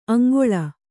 ♪ āŋgoḷa